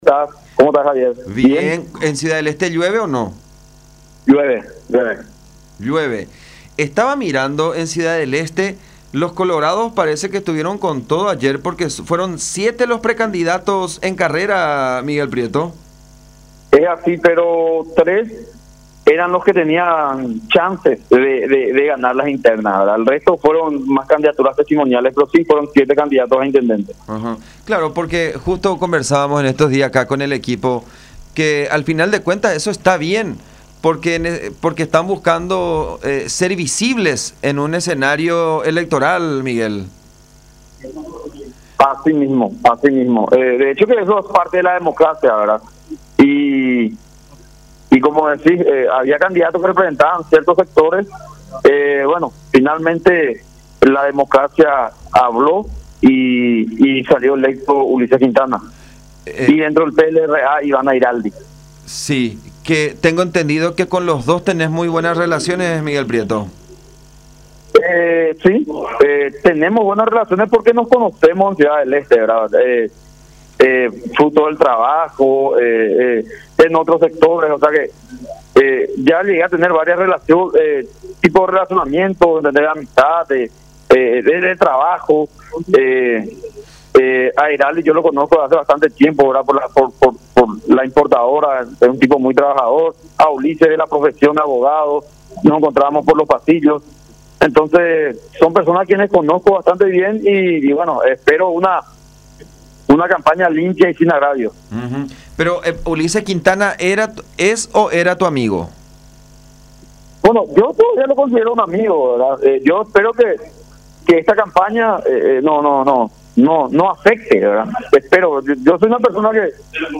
Si eso implica vender su alma al diablo, estarán dispuesto a hacerlo”, expresó Prieto en conversación con Todas Las Voces por La Unión.